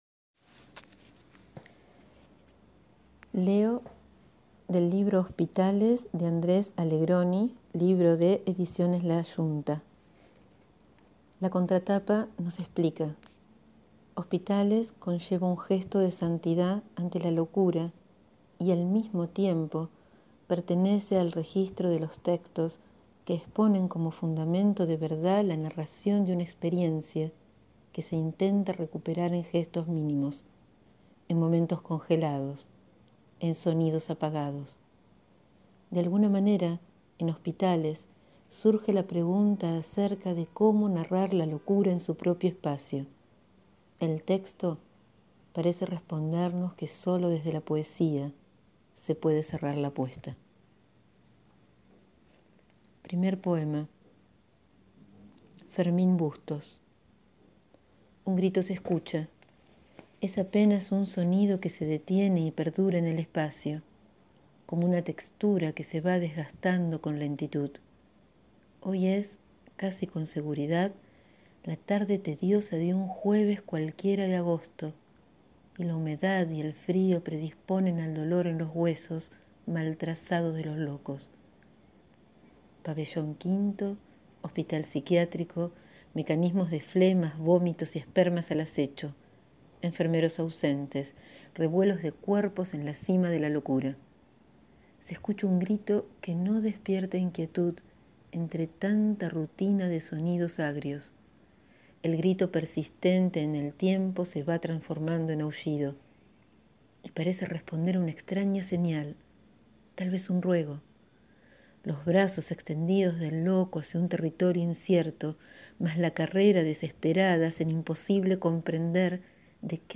Hoy les leo del libro «Hospitales» de Andrés H. Allegroni el poema «Fermín Bustos».